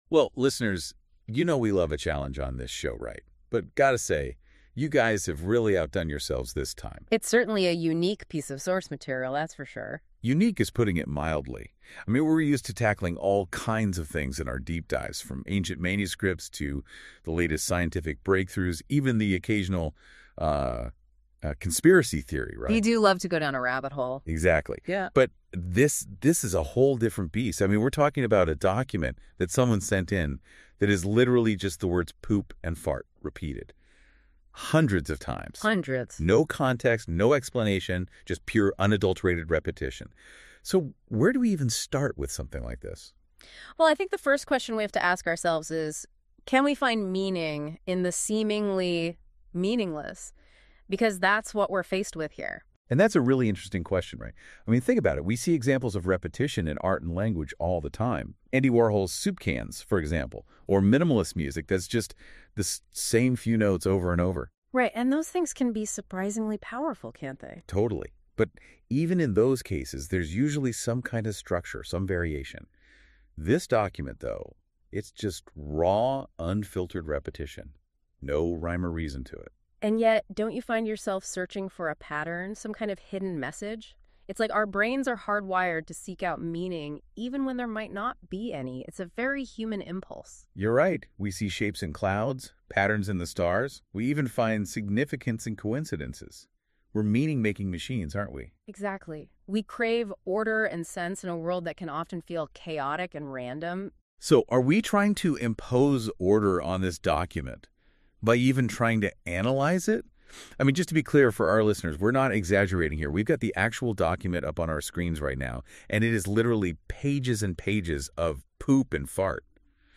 The background is this: Google has a new AI called NotebookLM that, when fed material, generates a simulated audio podcast of two very stereotypical podcasters discussing it.
Despite the occasional flaws, like the "hosts'" oddly-placed laughter, mispronounced words, or occasional cut-off sentences, the "podcast" was definitely far more natural and human-seeming than a lot of AI output.